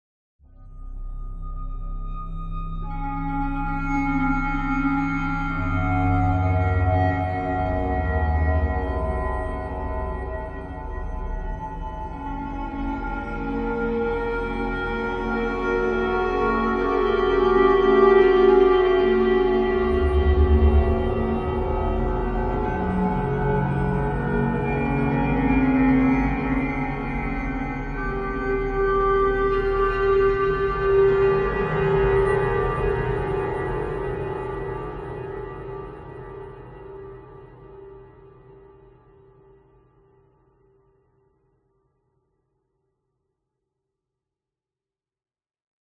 Здесь вы найдете как абстрактные шумы, так и более структурированные аудиоиллюзии.
Звук помрачения сознания